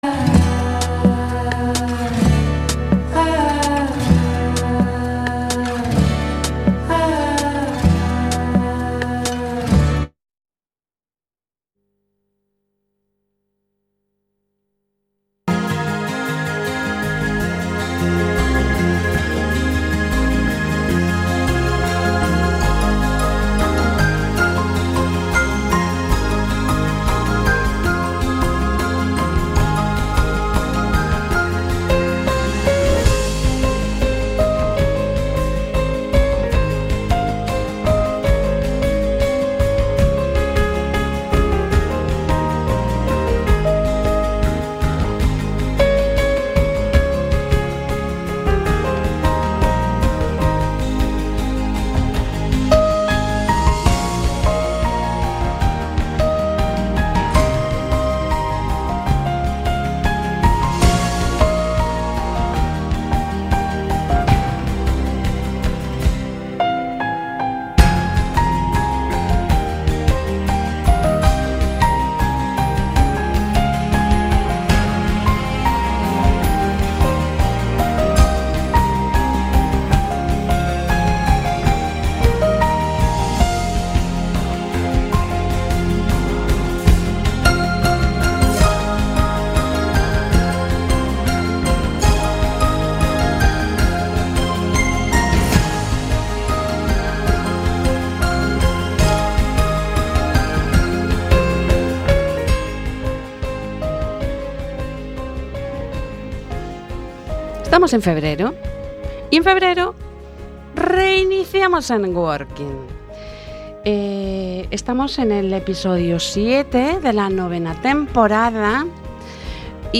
En el Estudio Rafaela Hervada de CUACfm, en el programa Enworking, nos acompañan: